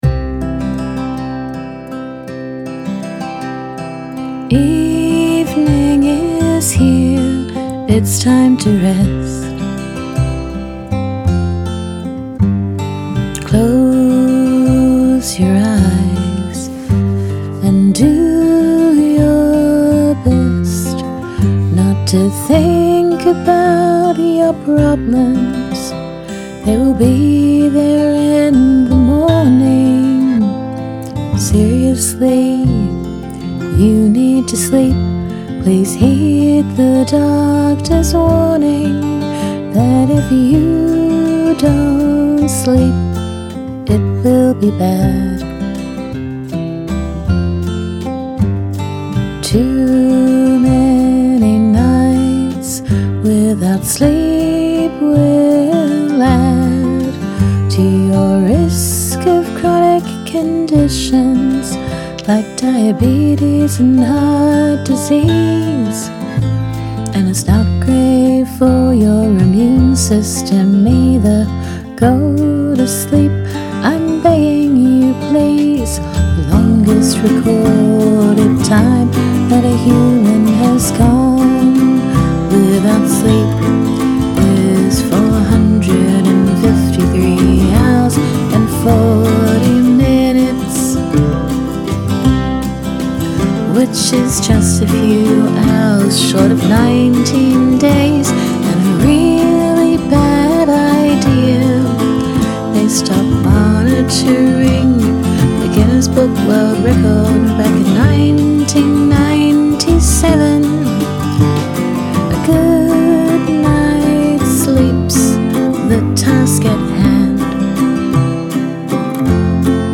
When you can't sleep and it's 2am and the prompt is lullaby, this is just what happens. Band in a Box arrangement, sung very quietly because it is 2am.
The music is so calming and pretty and chill, and the lyrics are absolutely not, and I love it.
The melody is so soothing that it actually makes a beautiful lullaby.
Great lyrics and a lovely, soothing melody.